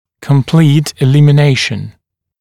[kəm’pliːt ɪˌlɪmɪ’neɪʃn] [ə-][кэм’пли:т иˌлими’нэйшн] [э-]полное устранение